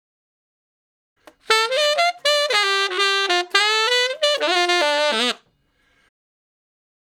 066 Ten Sax Straight (D) 33.wav